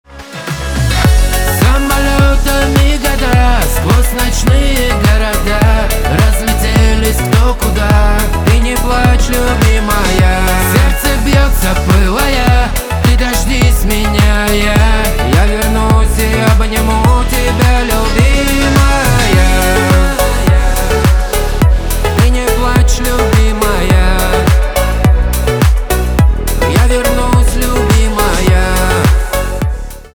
поп
чувственные , битовые